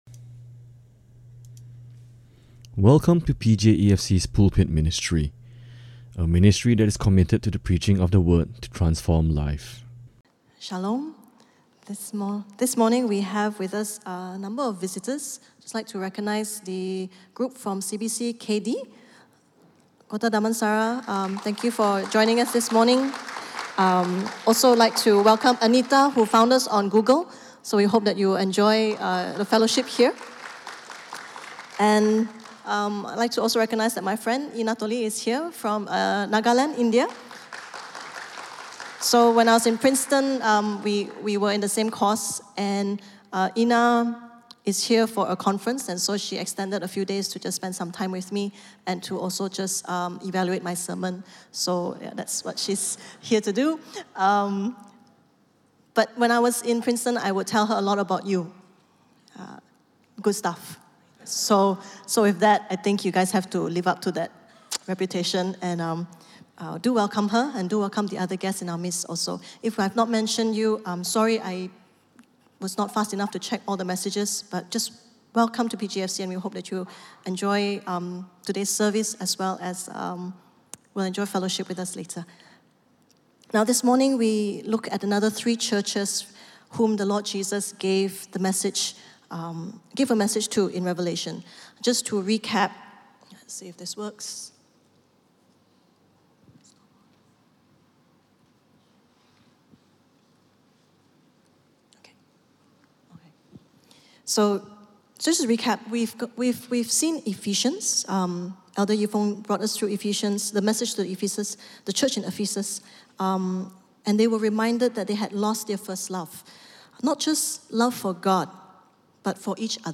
Listen to Sermon Only